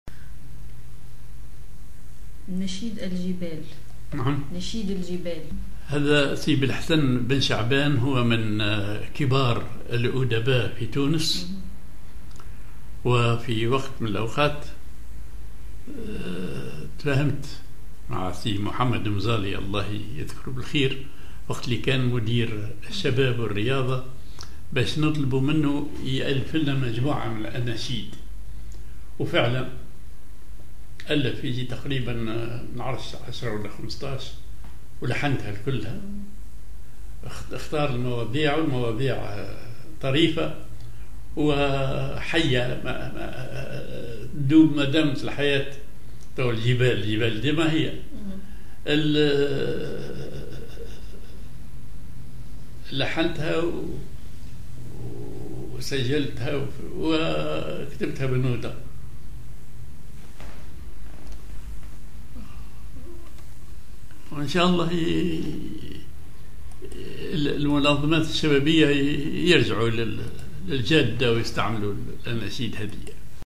Maqam ar محير العراق
genre نشيد